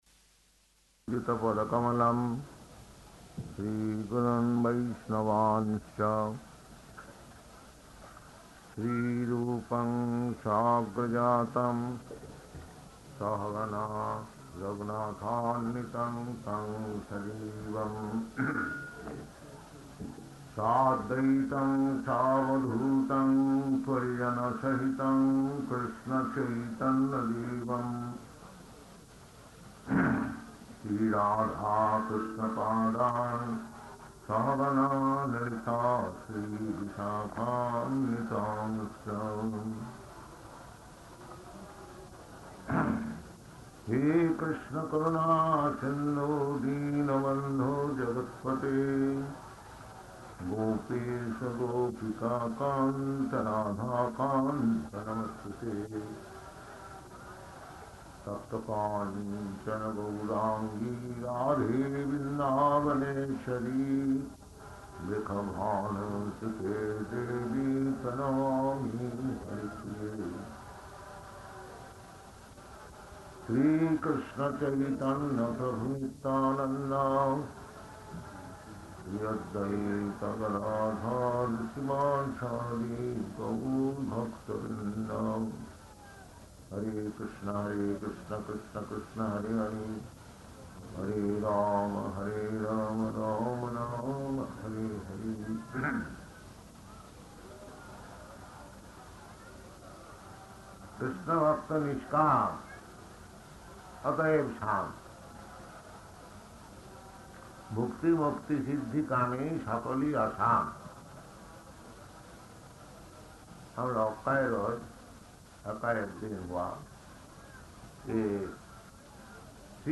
Śrī Caitanya-caritāmṛta Lecture in Hindi